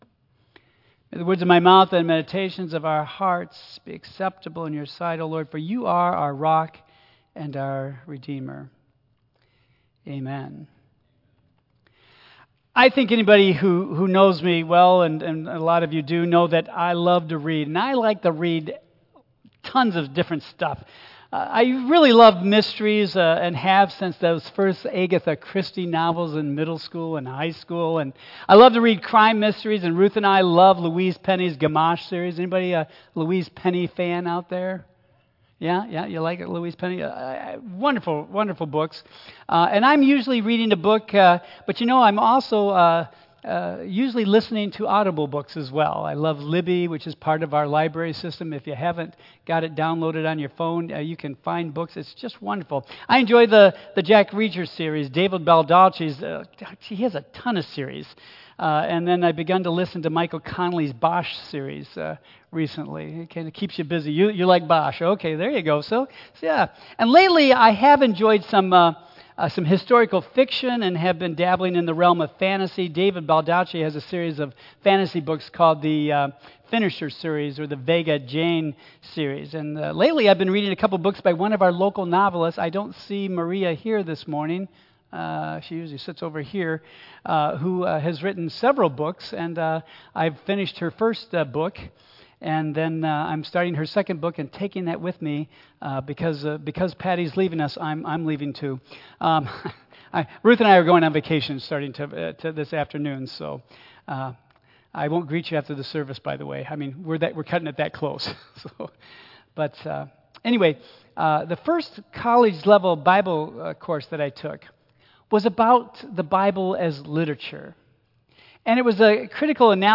Women of the Bible II Message Series The story of Eve plunges us immediately into the difficulties of understanding the Bible.